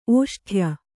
♪ ōṣṭhya